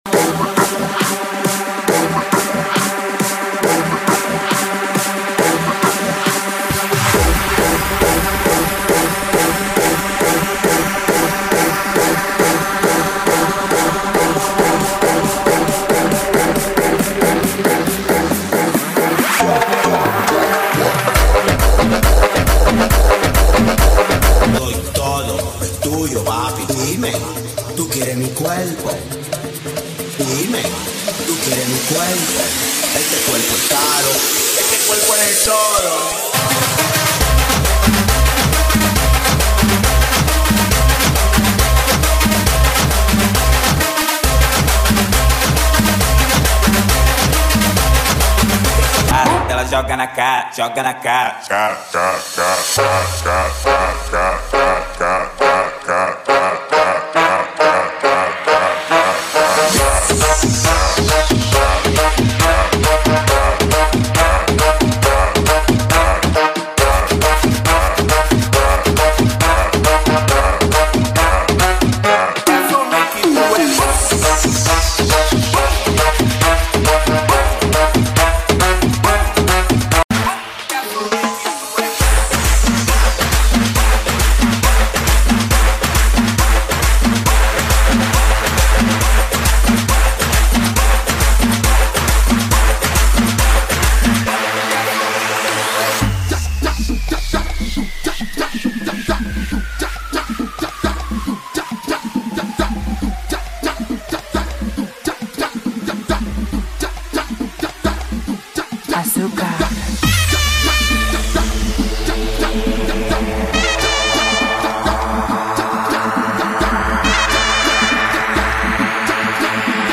GENERO: ANTRO CIRCUIT